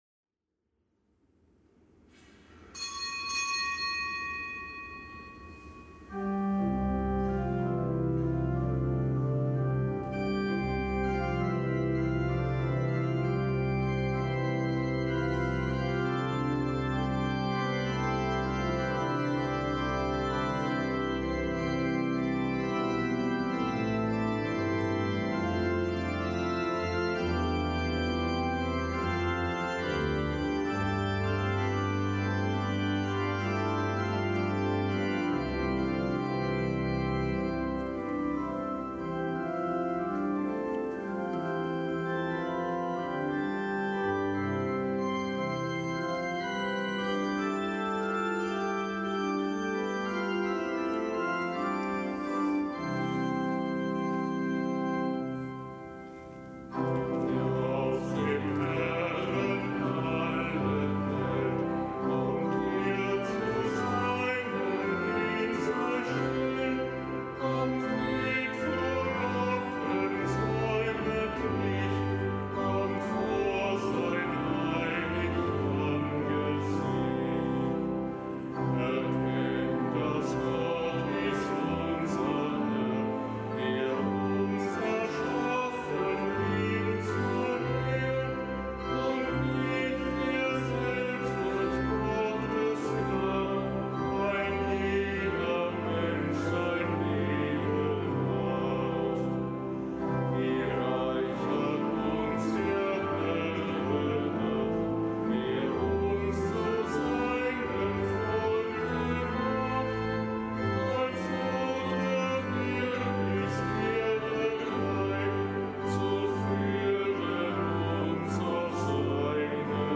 Kapitelsmesse am Gedenktag des Heiligen Bernhard von Clairvaux
Kapitelsmesse aus dem Kölner Dom am Gedenktag des Heiligen Bernhard von Clairvaux, Abt, Kirchenlehrer (RK;GK).